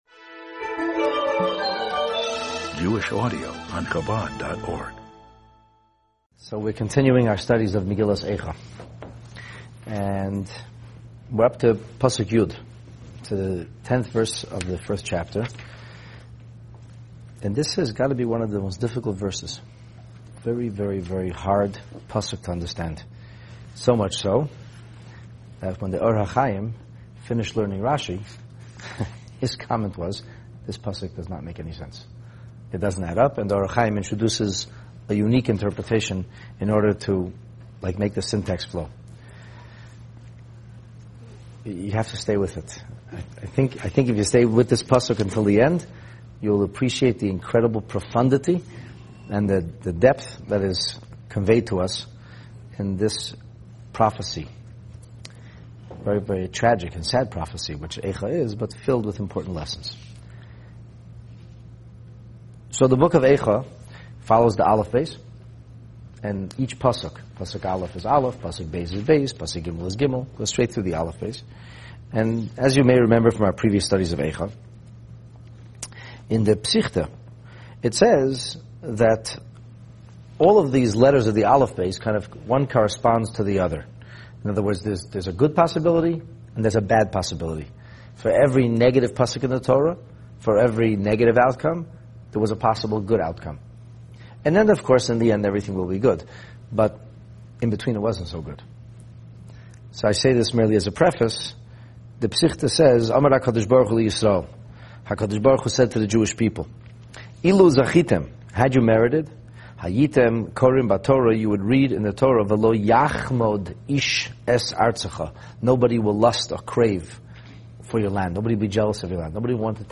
This class on Megillat Eicha focuses on verse 10 of the first chapter.